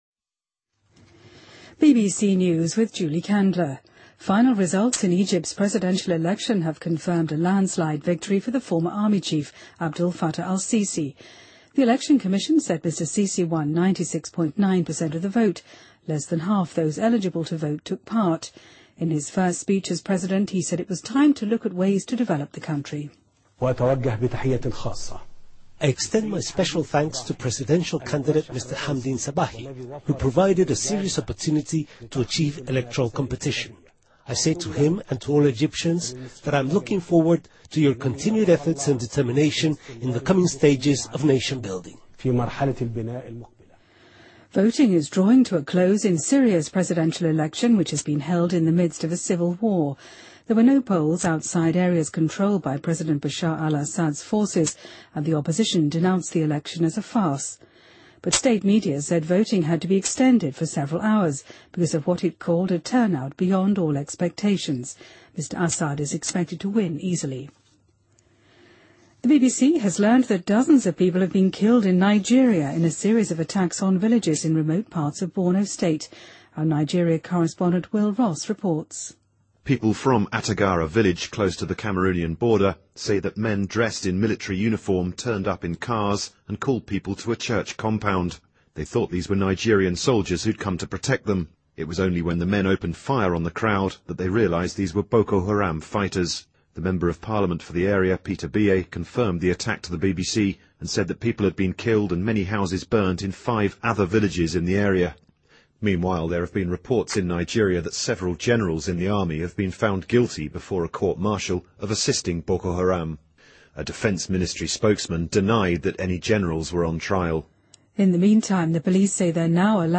BBC news,塞西当选埃及总统 得票率96.9%